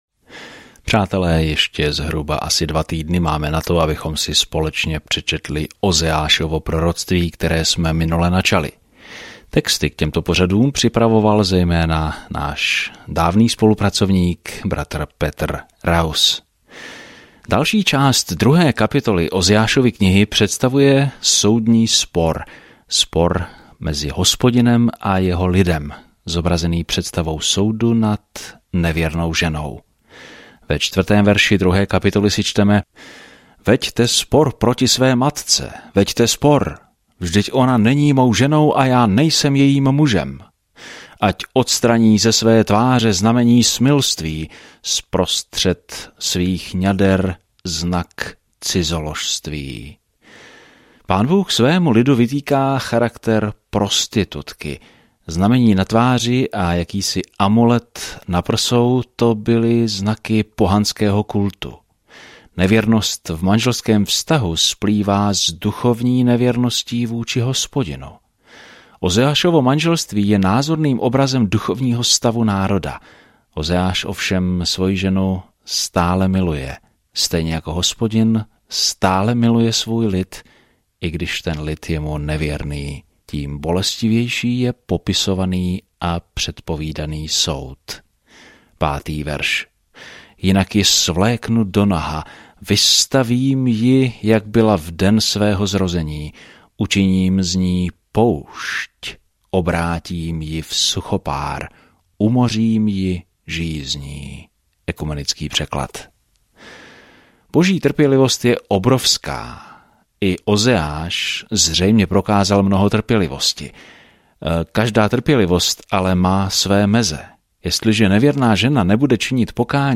Písmo Ozeáš 2:6-19 Den 1 Začít tento plán Den 3 O tomto plánu Bůh použil Ozeášovo bolestné manželství jako ilustraci toho, jak se cítí, když je mu jeho lid nevěrný, a přesto se zavazuje je stále milovat. Denně cestujte Ozeášem, posloucháte audiostudii a čtete vybrané verše z Božího slova.